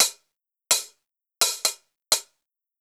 Closed Hats
HIHAT_SNEAK_ATTACK.wav